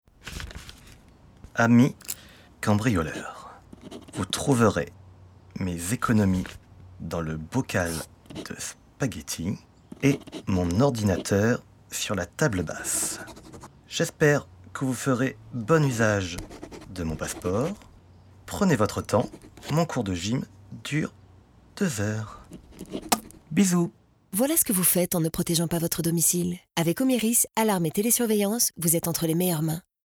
Bandes-son
Voix pub 1